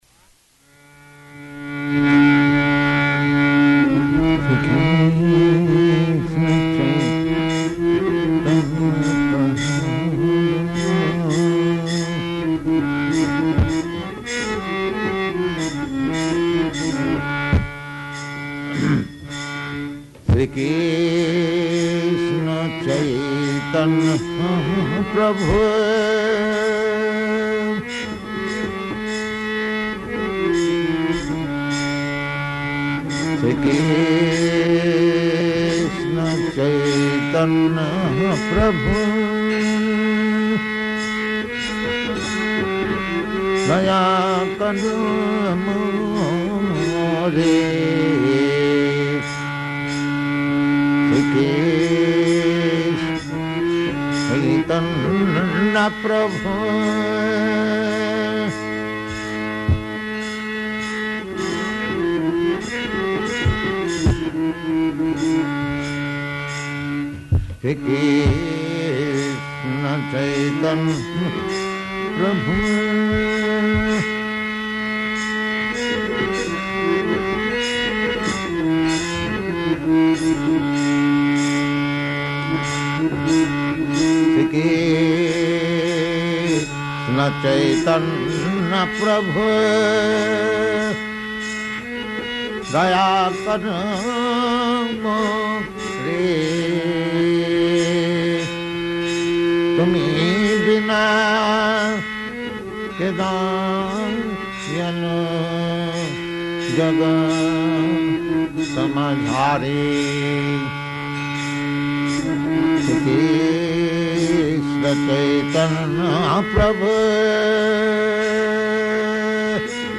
Location: Hawaii
Prabhupāda: [sings:]